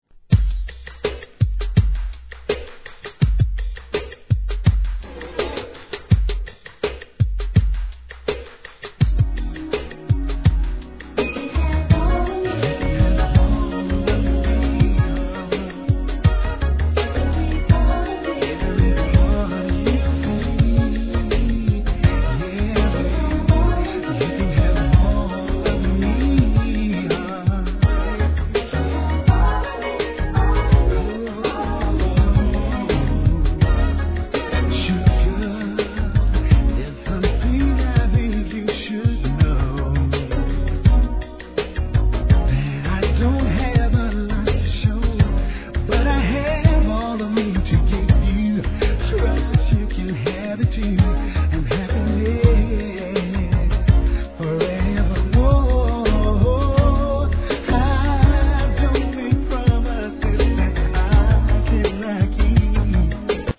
HIP HOP/R&B
1992年のGOODミディアム♪抜群のコーラス・ワークで聴かせます♪